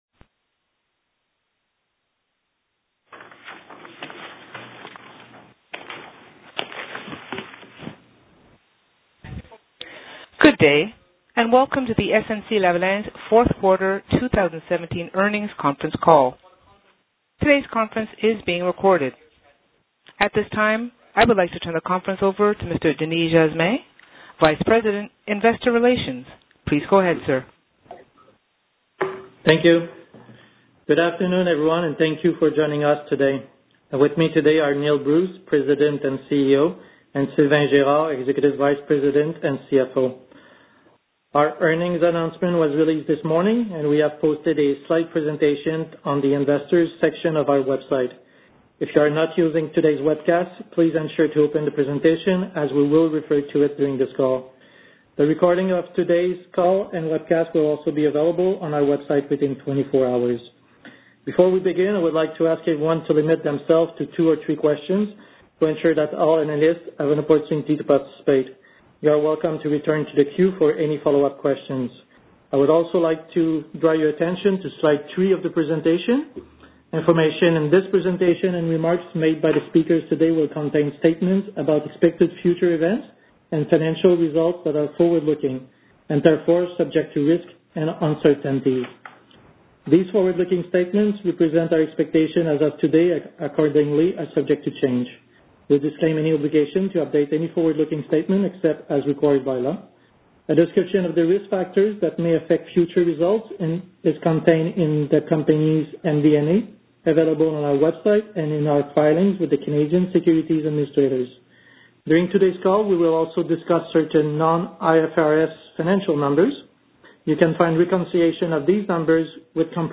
snc-lavalin-q4-2017-conference-call-recording.mp3